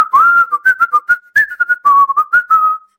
Human Whistling
A casual, melodic human whistle with a cheerful, wandering tune and natural breath pauses
human-whistling.mp3